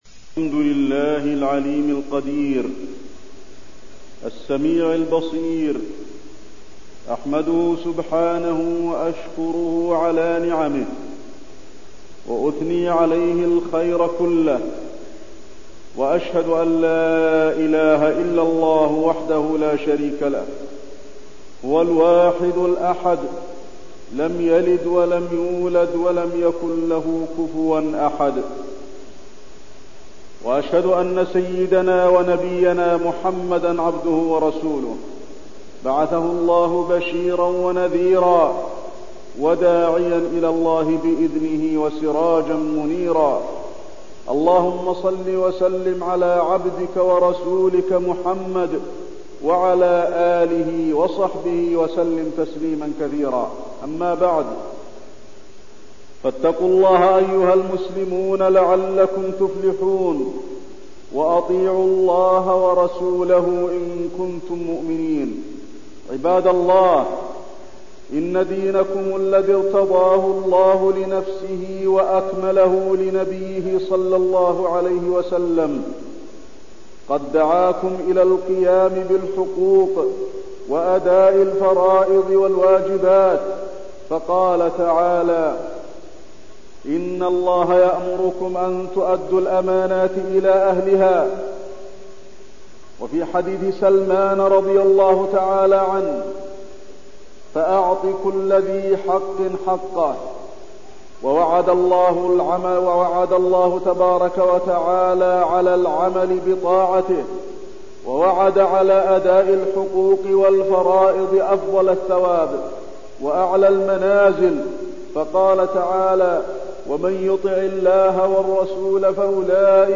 تاريخ النشر ١٦ ذو القعدة ١٤٠٥ هـ المكان: المسجد النبوي الشيخ: فضيلة الشيخ د. علي بن عبدالرحمن الحذيفي فضيلة الشيخ د. علي بن عبدالرحمن الحذيفي أخوة المسلمين